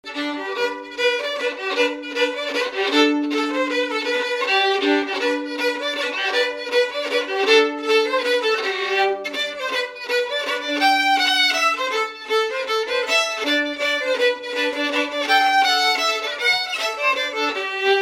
Résumé instrumental
danse : mazurka
Pièce musicale inédite